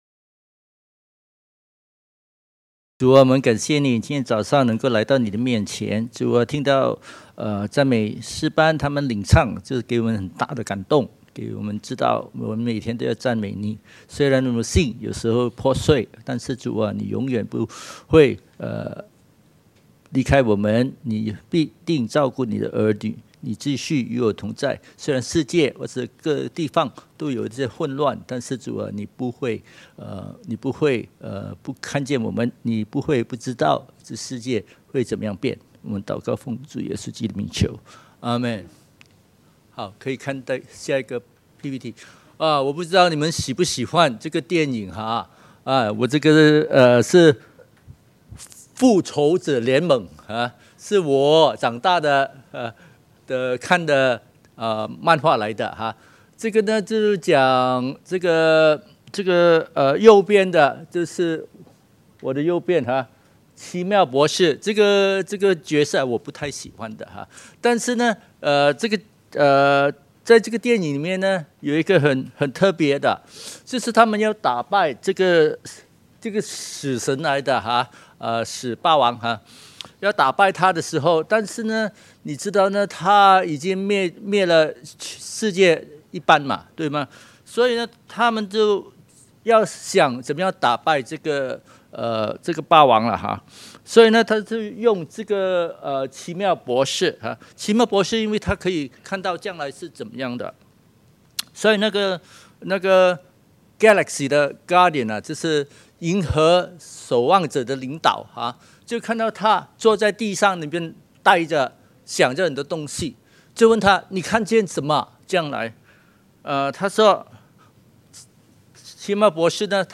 證道重溫